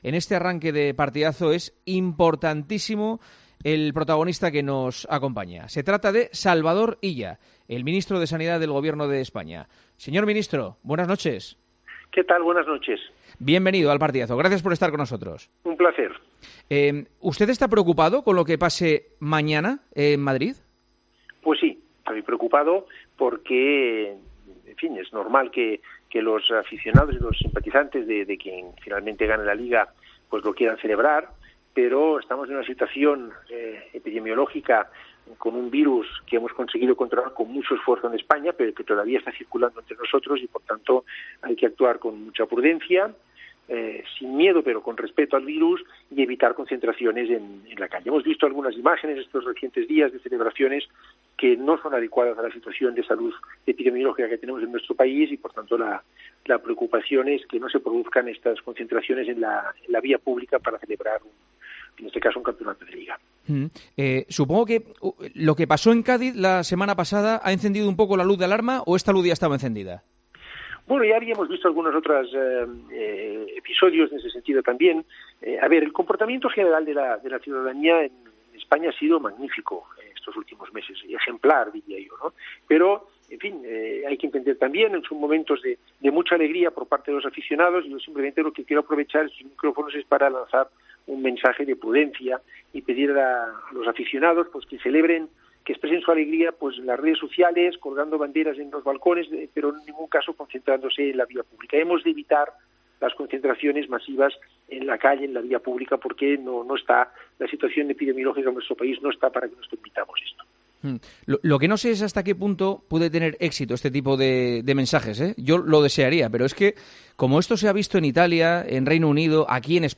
El Ministro de Sanidad está "preocupado" por lo que pueda pasar con el comportamiento de algunos aficionados con la celebración del título de LaLiga Santander esta semana. Salvador Illa , en la entrevista concedida este miércoles a Juanma Castaño en El Partidazo de COPE , no ocultó su inquietud por lo que pueda pasar: "Sí, estoy preocupado.